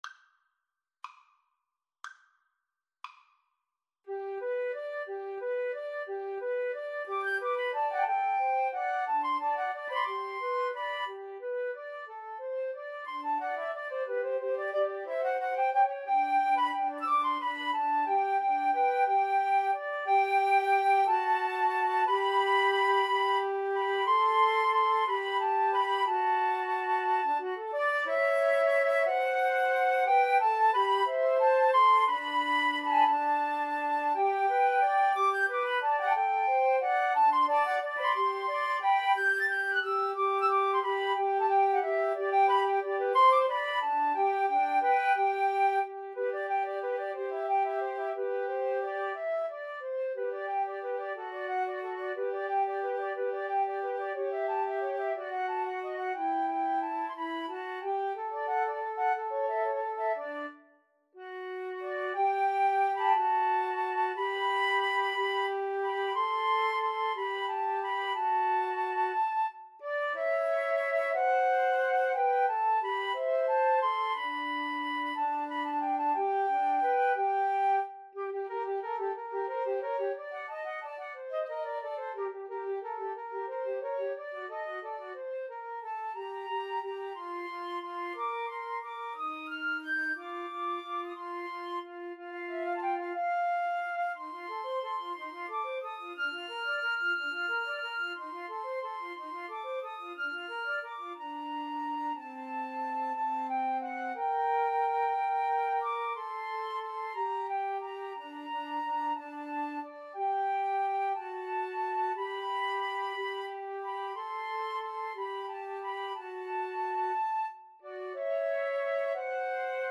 Flute 1Flute 2Flute 3
6/8 (View more 6/8 Music)
G major (Sounding Pitch) (View more G major Music for Flute Trio )
Molto lento .=c. 60
Classical (View more Classical Flute Trio Music)